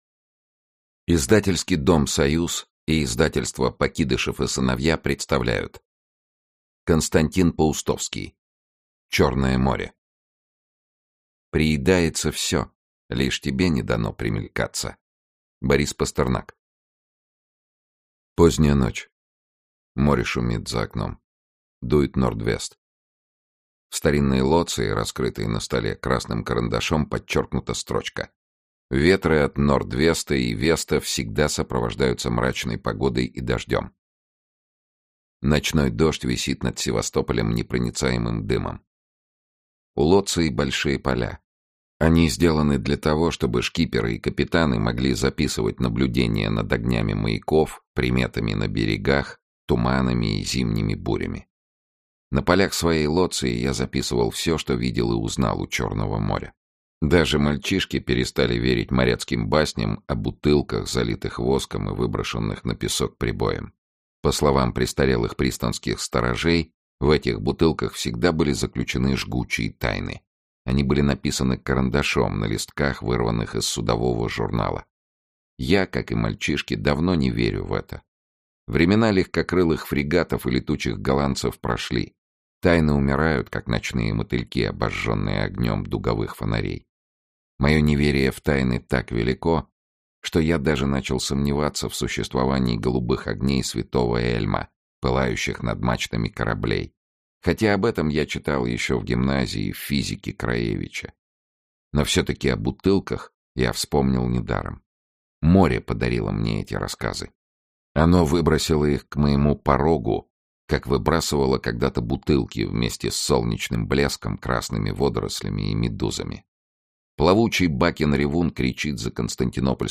Aудиокнига Черное море Автор Константин Паустовский Читает аудиокнигу Сергей Чонишвили.